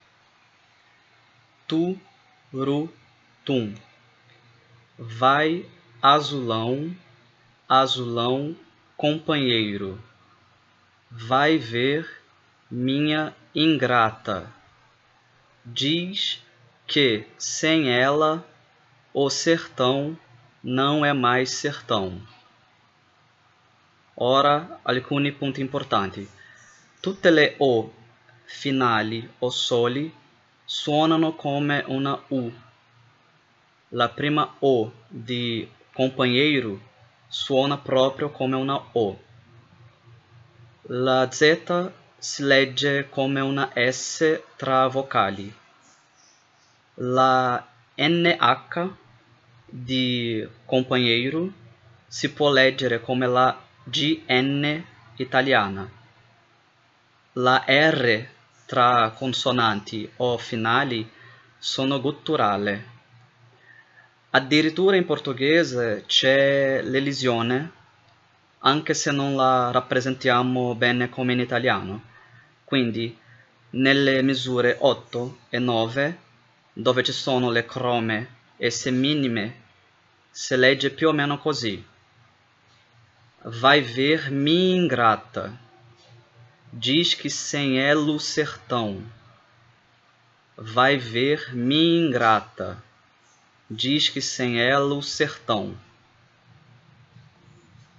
azulao pronuncia